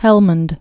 (hĕlmənd)